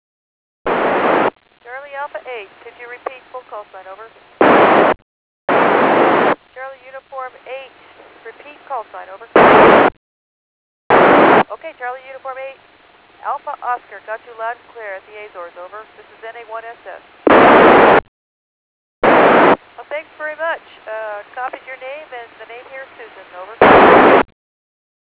Here are the qsl i have received from the first qso i have made with Susan Helms, NA1SS: